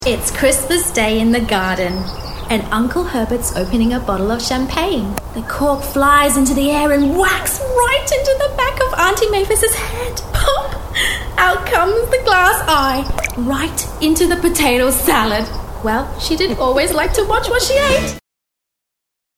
Female
I am a Sydney Australia based singer voice over artist with a natural Australian accent.
My voice has been described as friendly, trustworthy, convincing, authoritative, authentic, enthusiastic, enticing, cut from a different cloth with a sassy certainty by my clients and peers.
Narration